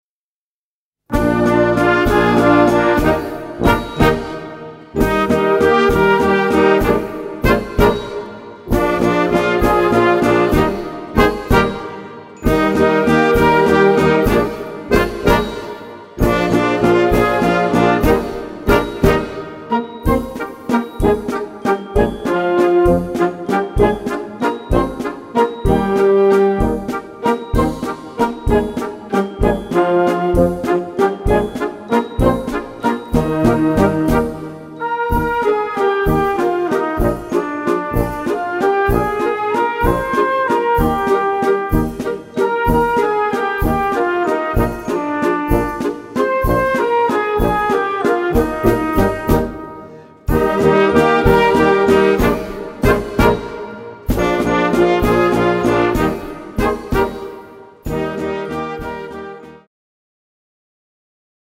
A4 Besetzung: Blasorchester Zu hören auf